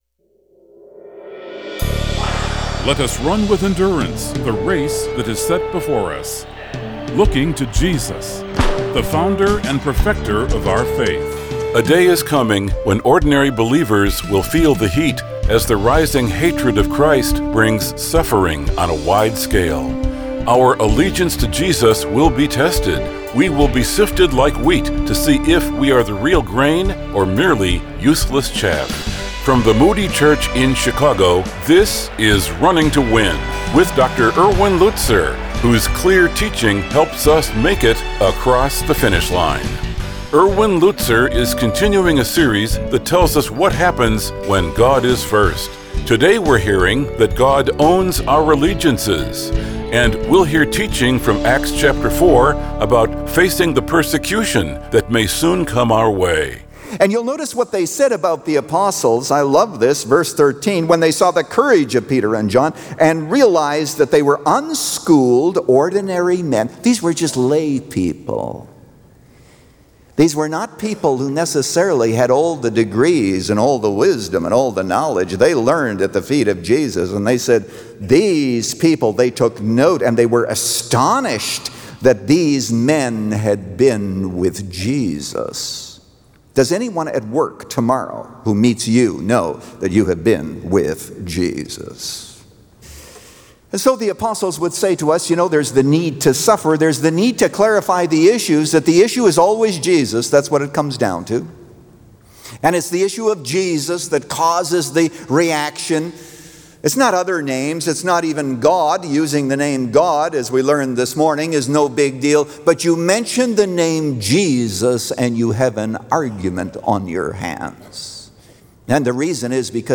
Since 1998, this 15-minute program has provided a Godward focus.